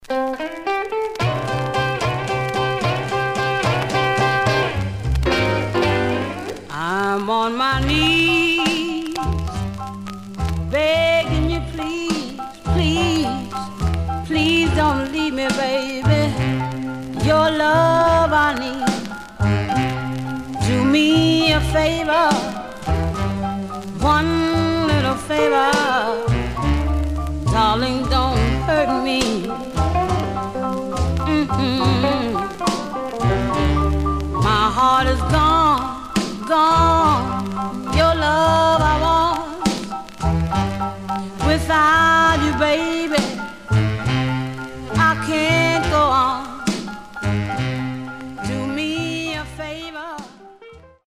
Stereo/mono Mono
Rythm and Blues